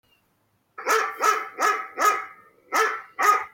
Guau, guau
PERRO (audio/mpeg)